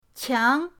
qiang2.mp3